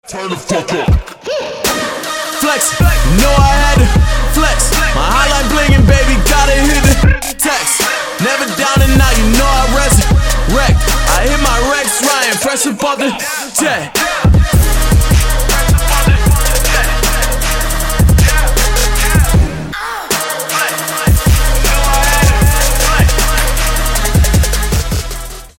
• Качество: 320, Stereo
Хип-хоп
Trap
Rap
Bass
трэп